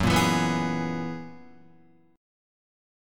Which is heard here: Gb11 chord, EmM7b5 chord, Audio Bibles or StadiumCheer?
Gb11 chord